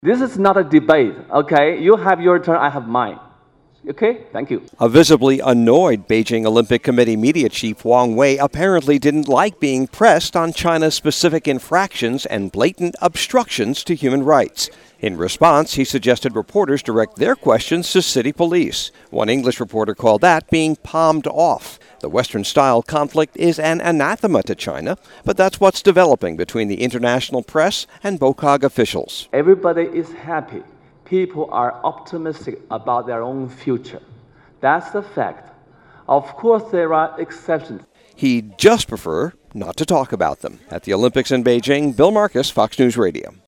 2008 Beijing Olympics, China, Fox News Radio, Selected Reports: